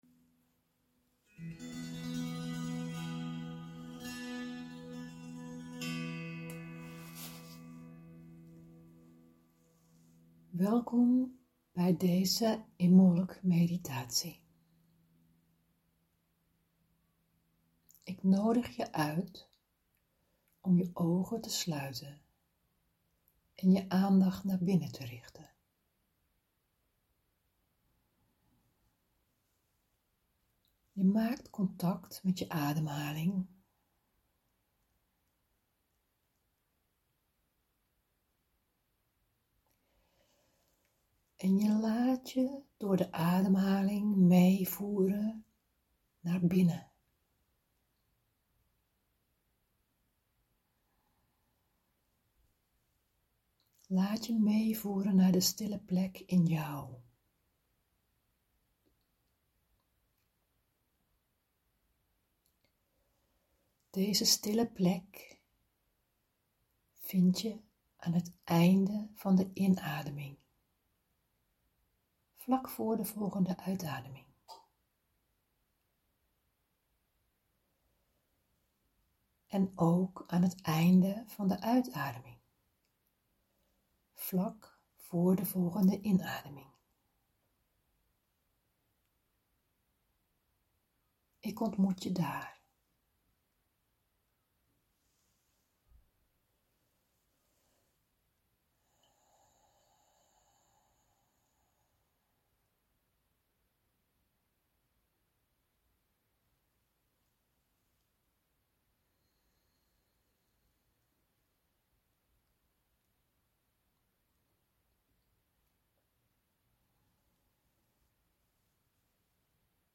Wil je je afstemmen op de tijd van het jaar en je laten inspireren door de natuur, luister dan hieronder naar de Imbolc geleide meditatie die ik voor je ingesproken heb.
Imbolc-meditatie.mp3